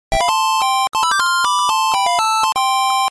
勝利時の音 [効果音]
ファミコン音です。